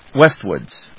wést・wards /‐wɚdz‐wədz/